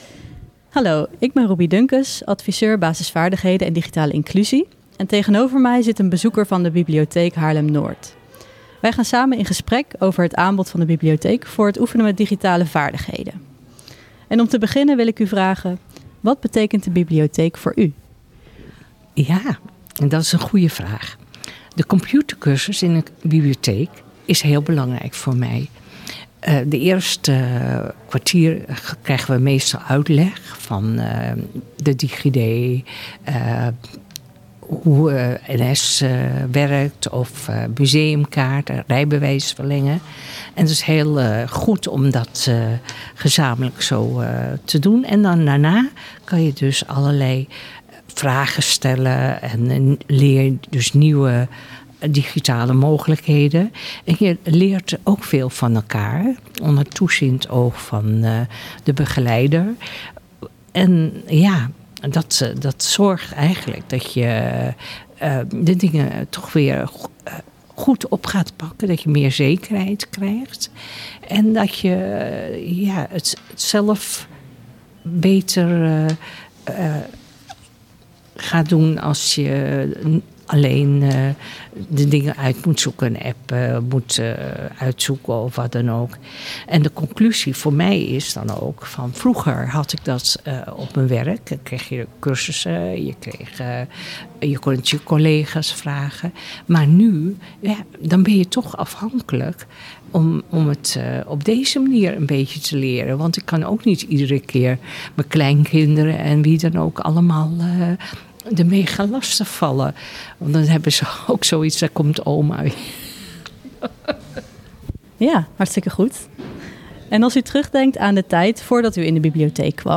Van-IDO-naar-aanbod-in-Bibliotheek-Haarlem-Noord.mp3